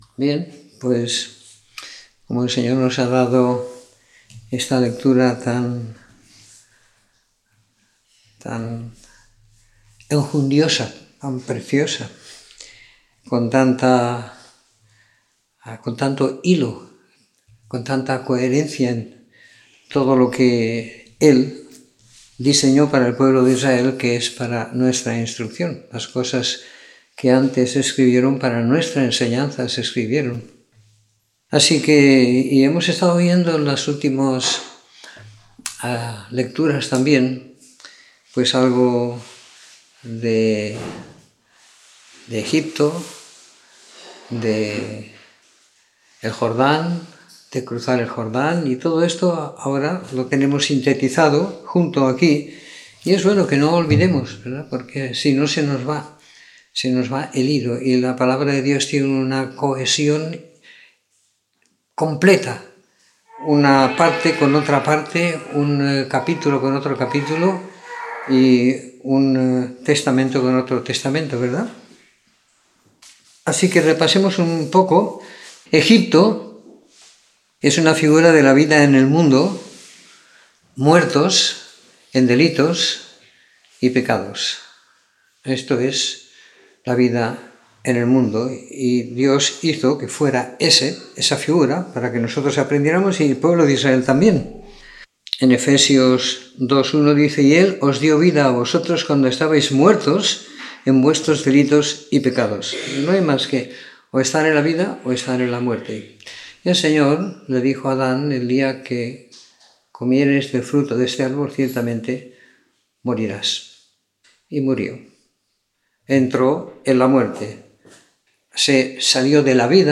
Comentario en el libro de Josué siguiendo la lectura programada para cada semana del año que tenemos en la congregación en Sant Pere de Ribes.